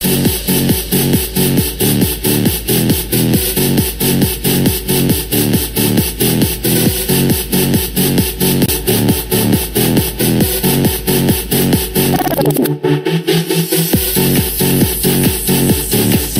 - Muzyka elektroniczna